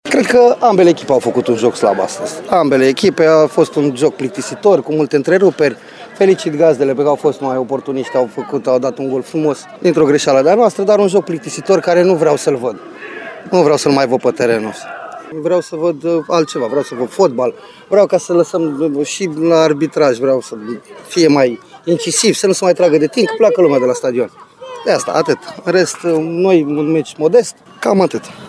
Pentru tehnicianul oaspeților, Flavius Stoican, meciul a fost plictisitor: